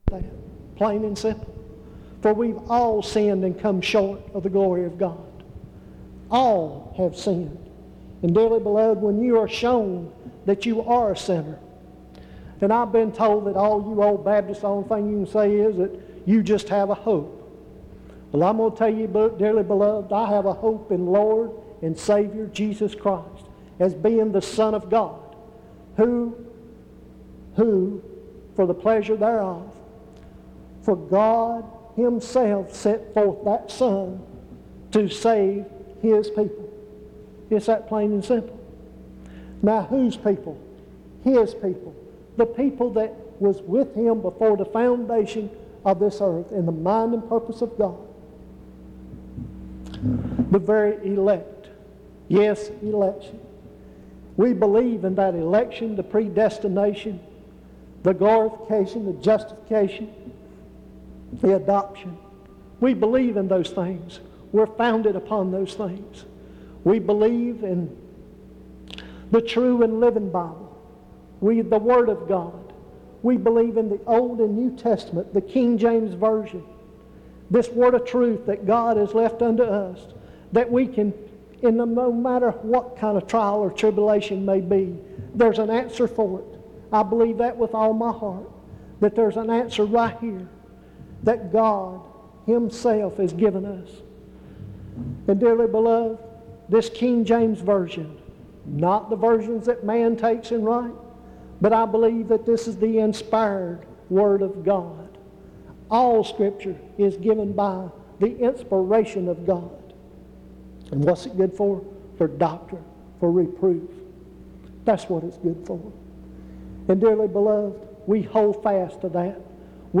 In Collection: Reidsville/Lindsey Street Primitive Baptist Church audio recordings Thumbnail Titolo Data caricata Visibilità Azioni PBHLA-ACC.001_083-B-01.wav 2026-02-12 Scaricare PBHLA-ACC.001_083-A-01.wav 2026-02-12 Scaricare